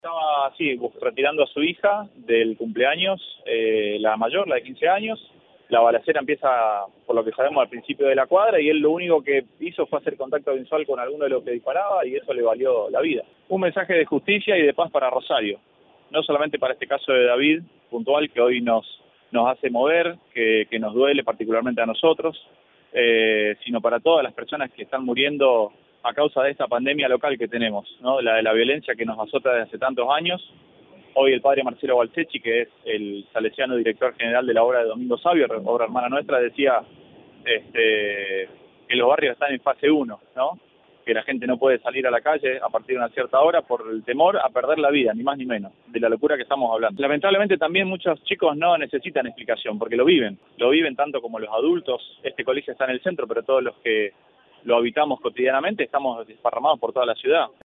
ante el móvil de Cadena 3 Rosario